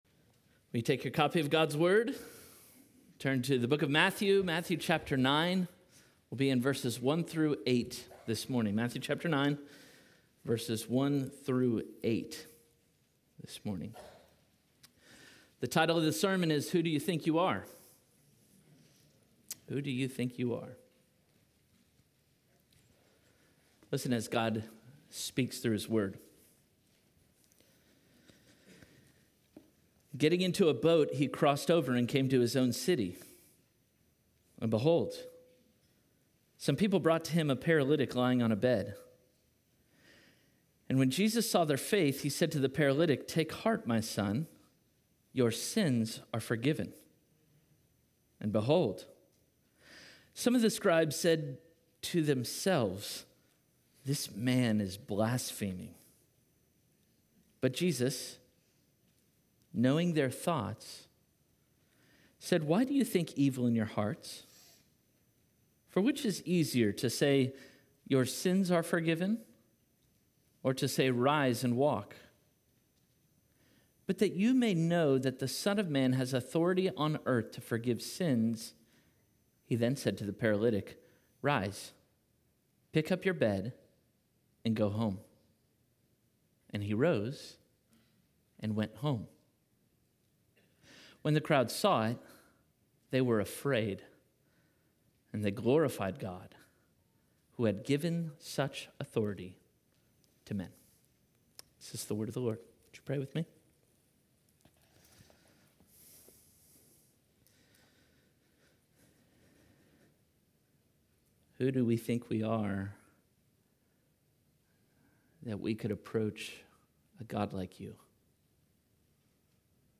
Parkway Sermons Who Do You Think You Are?